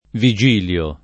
viJ&lLo] pers. m. — così (non Virgilio) il papa degli anni 537-555, il santo vescovo di Trento (sec. IV-V), il top. San Vigilio (A. A., Trent., Ven., Lomb.), il nome di vari personaggi spec. trentini (es. il giurista Francesco Vigilio Barbacovi, 1738-1825, e il filologo Vigilio Inama, 1835-1912)